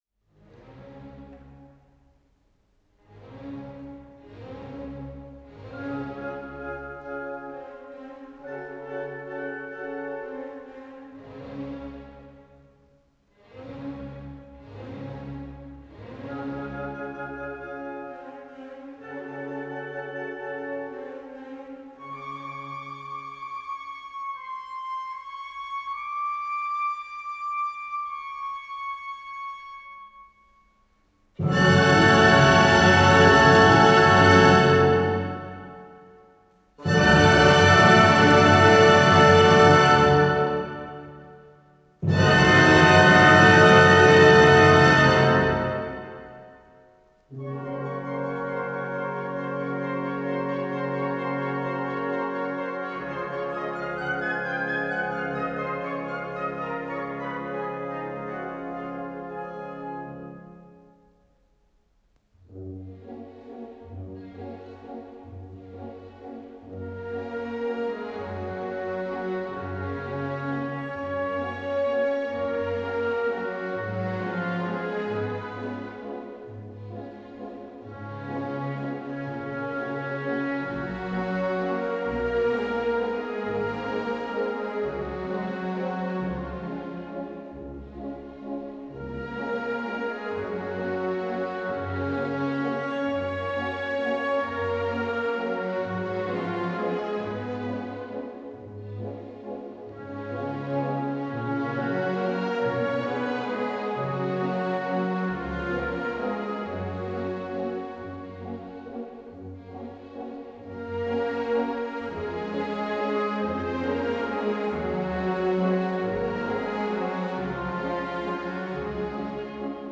Va', pensiero - base orchestrale